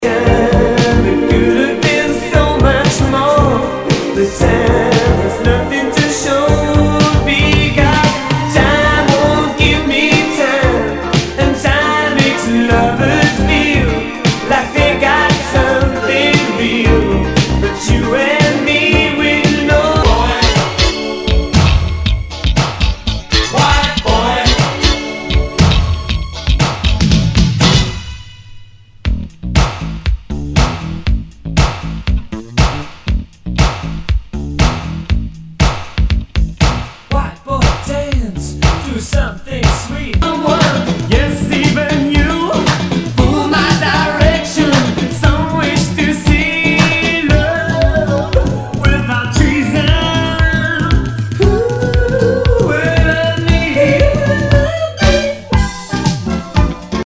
ROCK/POPS/INDIE
ナイス！シンセ・ポップ！